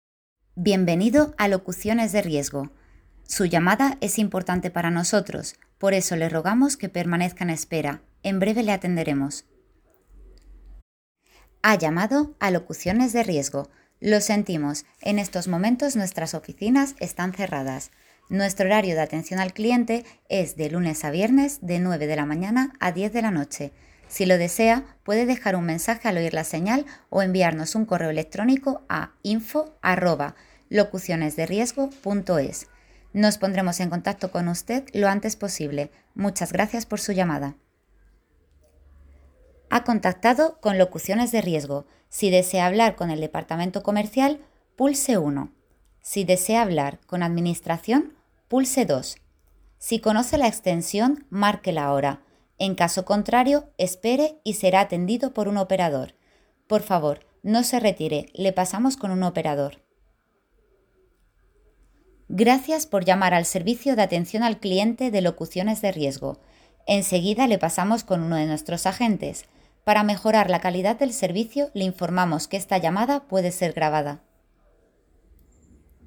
kastilisch
Sprechprobe: Industrie (Muttersprache):
Centralita-locuciones-de-riesgo.mp3